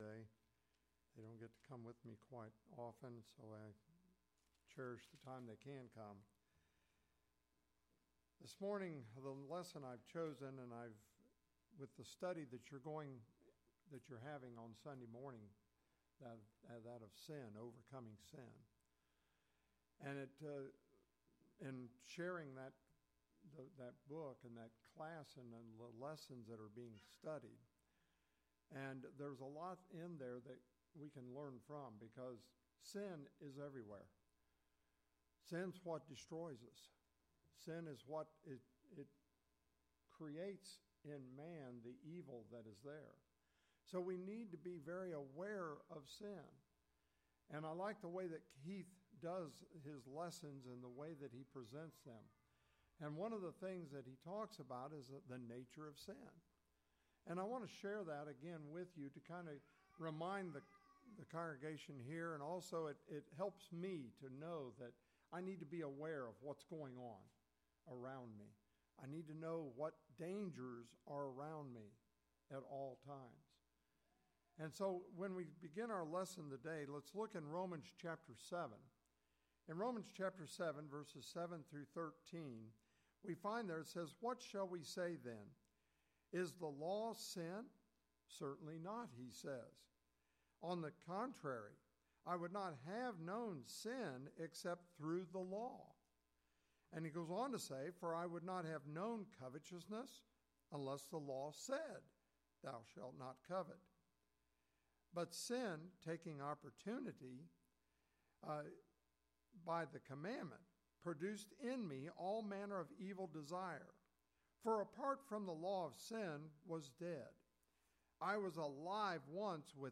The sermon’s goal was to warn about the reality and danger of sin.